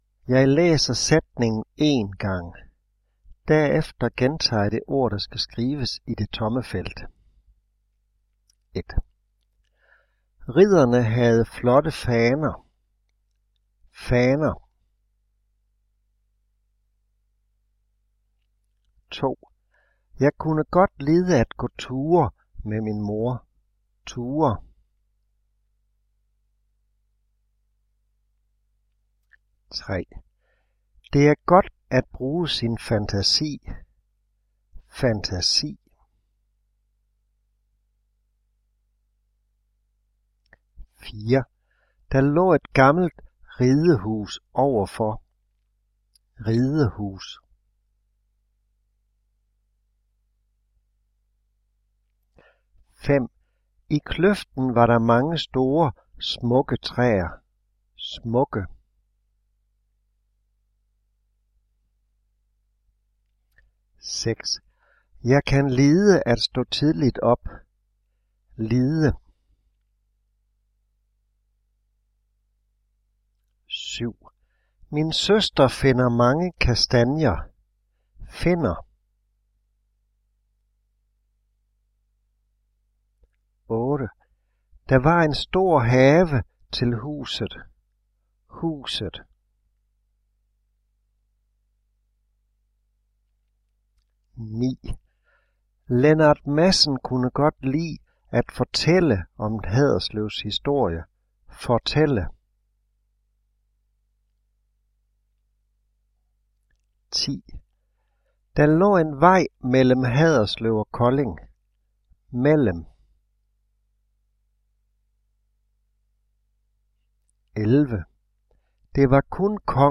Trin 1 - Lektion 1 - Diktat
Jeg læser sætningen én gang. Derefter gentager jeg det ord, der skal skrives i det tomme felt.